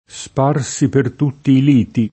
lido [l&do] s. m. («spiaggia») — anche elem. di numerosi toponimi — poet. lito [l&to]: Tornate a riveder li vostri liti [torn#te a rrived%r li v0Stri l&ti] (Dante); Sparsi per tutti i liti [